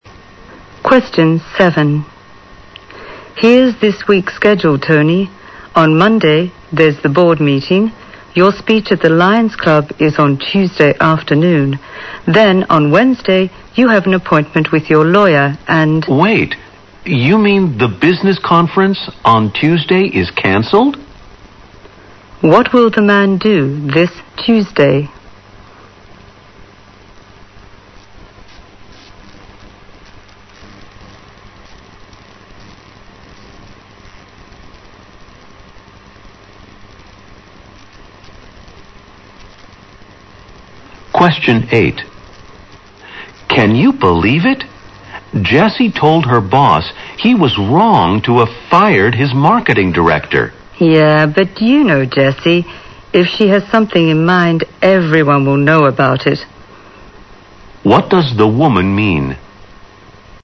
在线英语听力室067的听力文件下载,英语四级听力-短对话-在线英语听力室